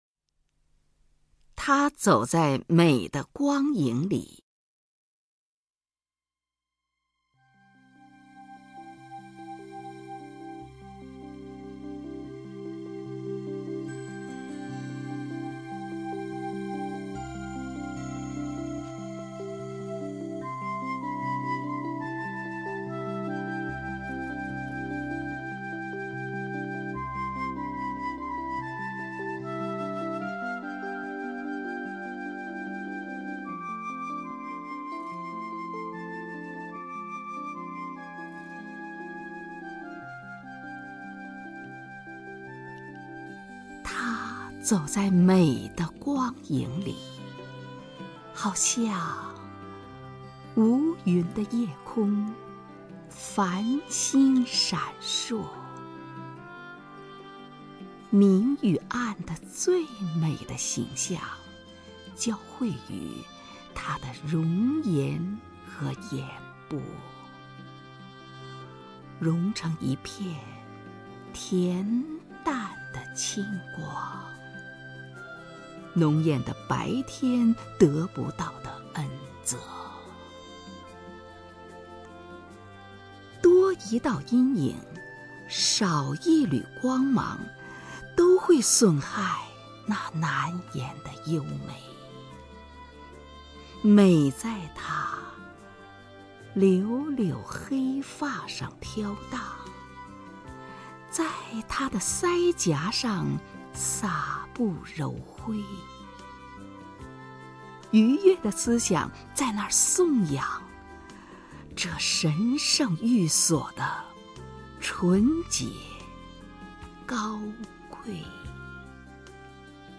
首页 视听 名家朗诵欣赏 虹云
虹云朗诵：《她走在美的光影里》(（英）乔治·戈登·拜伦)